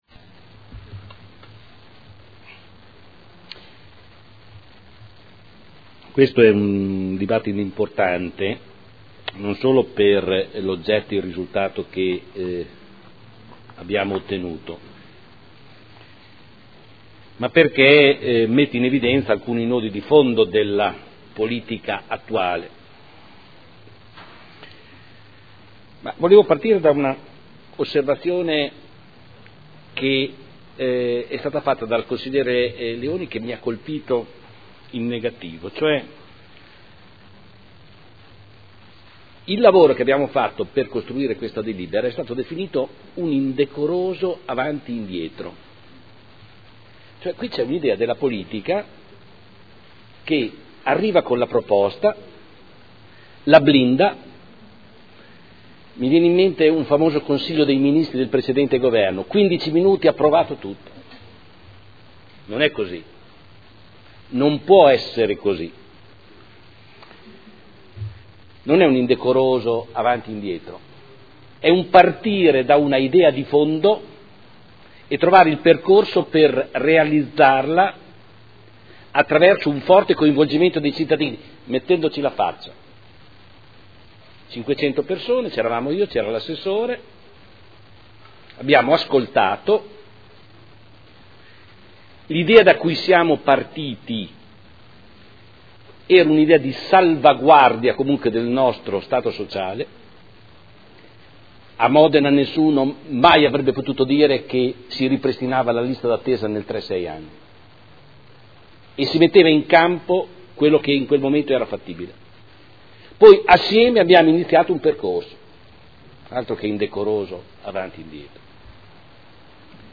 Seduta del 03/05/2012. Dibattito su proposta di deliberazione, emendamenti e Ordine del Giorno sulle scuole d'infanzia comunali